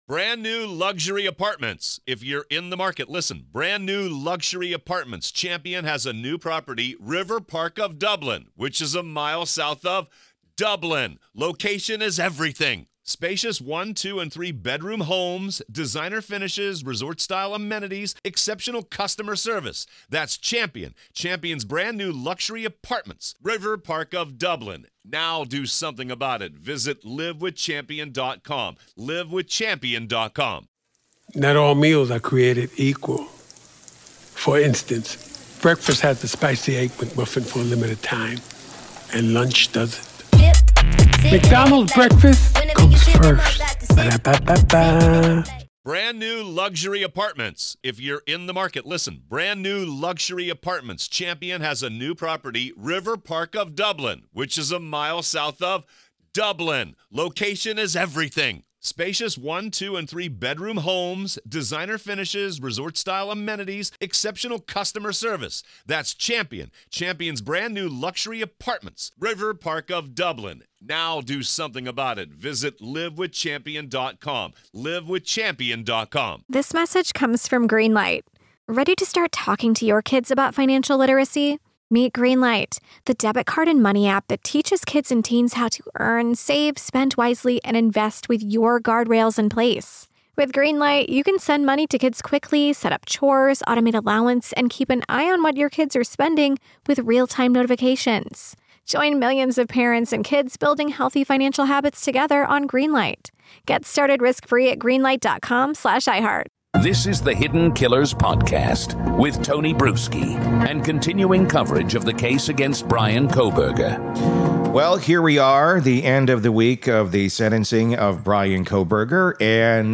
four explosive conversations